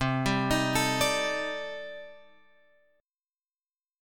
C Minor 6th Add 9th
Cm6add9 chord {8 10 x 8 10 10} chord